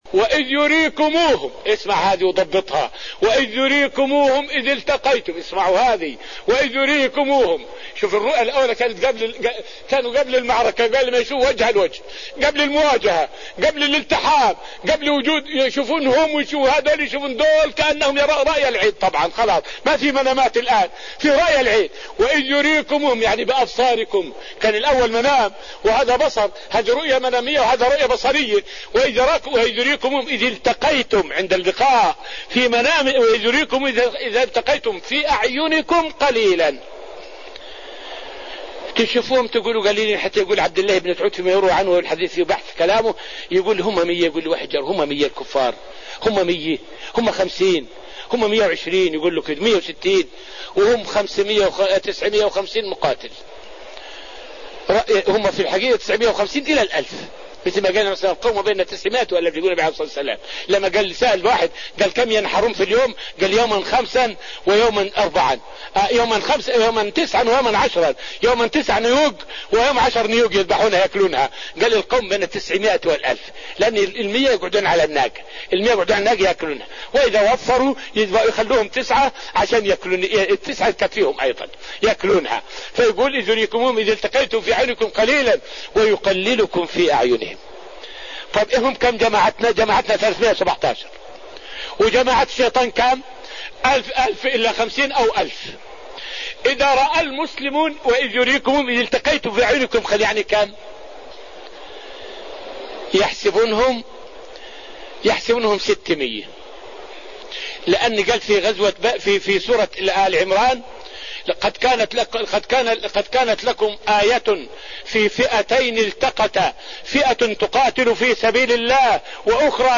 فائدة من الدرس الرابع من دروس تفسير سورة الأنفال والتي ألقيت في رحاب المسجد النبوي حول معنى قوله {وإذ يريكموهم إذ التقيتم في أعينكم قليلا}.